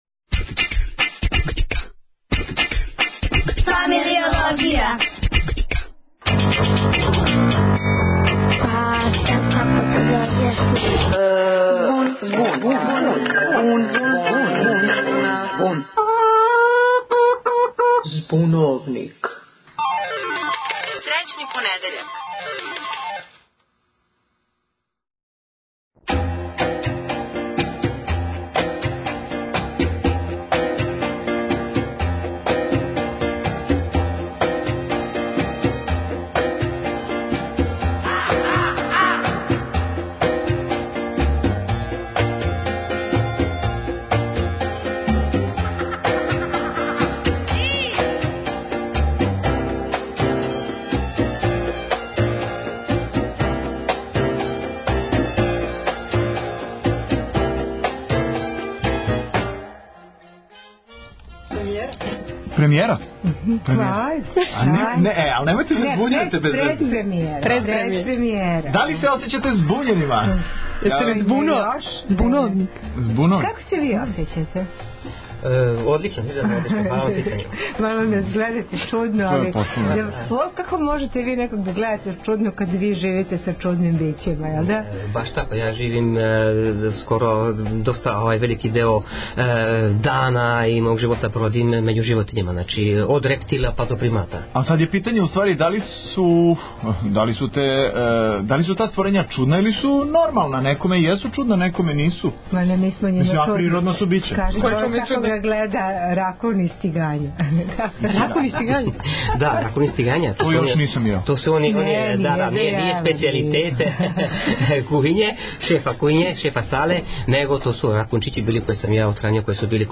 Имаћемо три нивоа реализације (три водитеље и даље у студију) са снимљеним сегментима на одређену тему.
Најпре на то питање одговарају најмлађи.
Следећи одгвор дају студенти књижевности. И на крају стручњак, зоолог.
С пуно звукова, ефеката и џинглова, биће то нови стари понедељак.